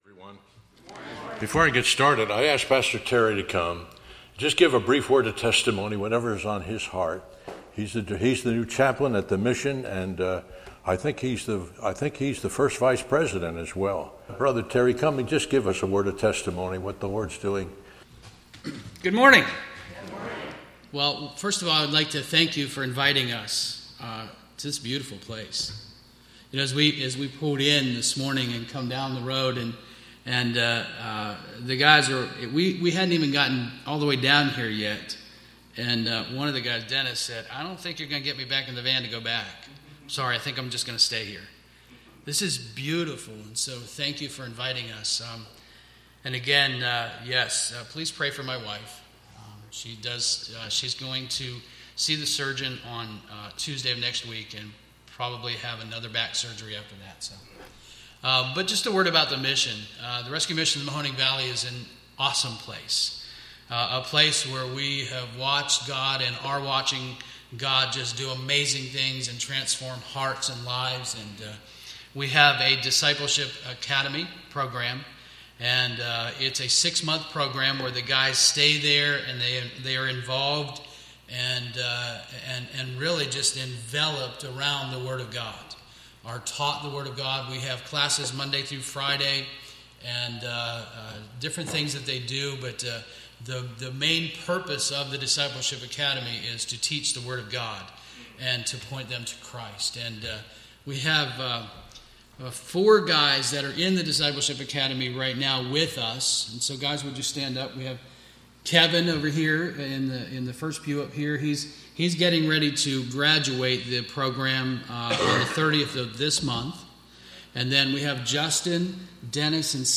Passage: Titus 2:11-15 Session: Morning Devotion